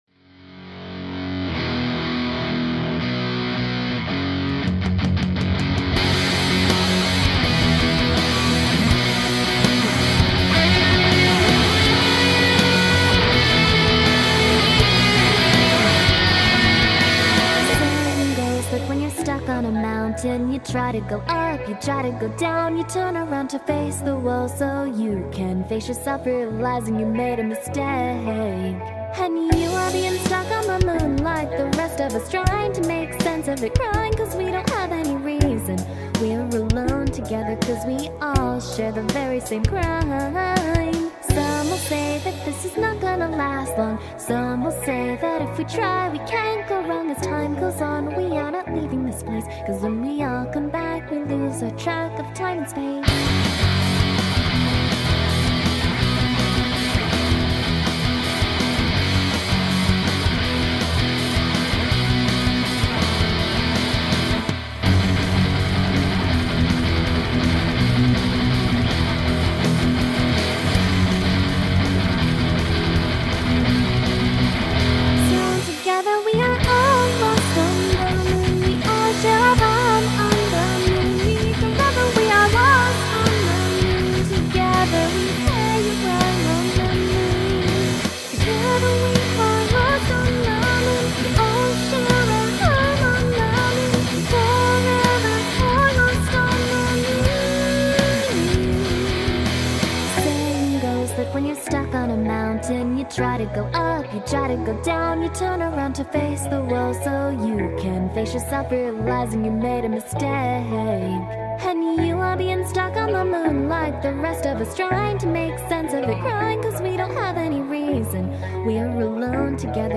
Youtube lowered sound quality in video.